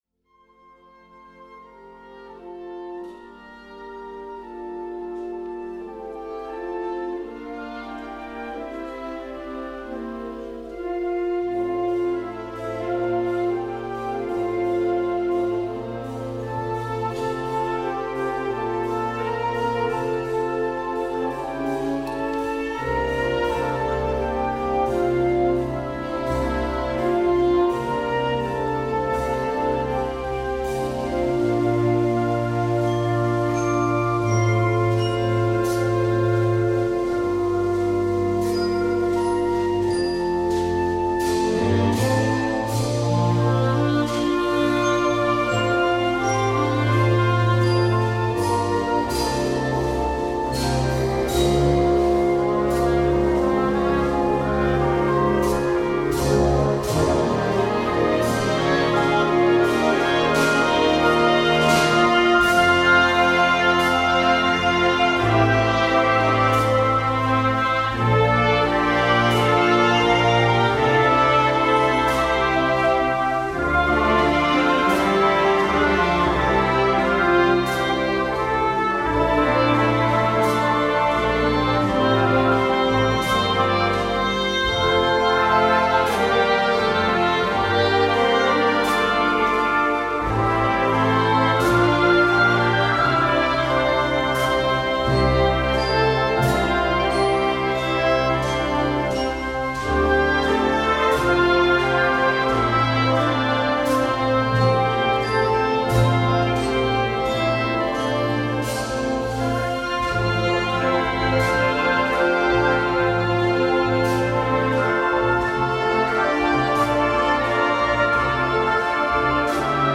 The new fresh arrangement
Includes optional Piano and Double Bass parts